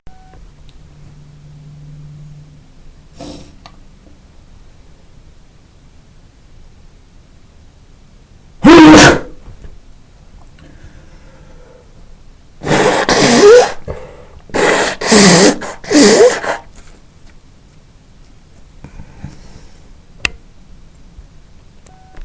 MALE WAVS
Sneeze & Blow | Simple |
sneezeandblow.MP3